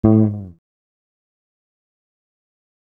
A FALL OFF.wav